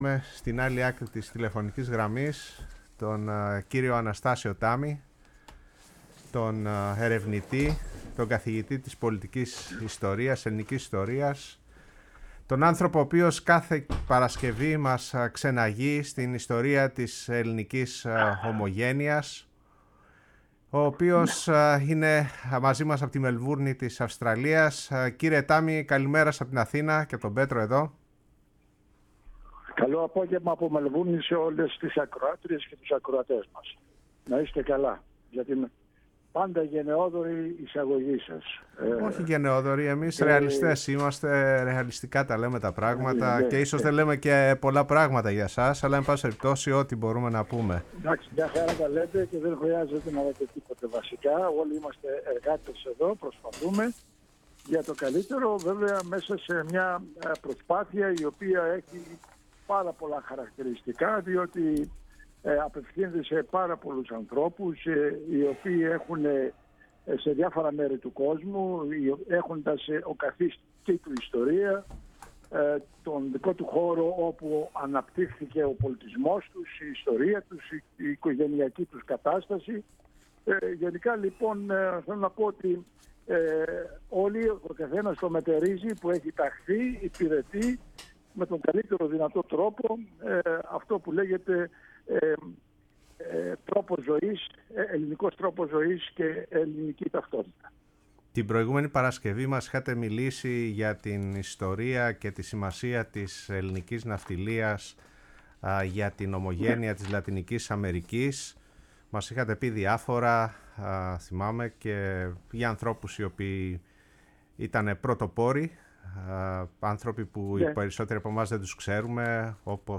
στο ραδιόφωνο της Φωνής της Ελλάδος